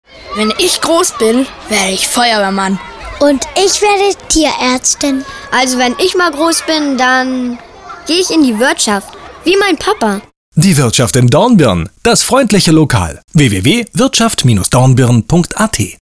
Hörfunk
zurgams hoerspots radio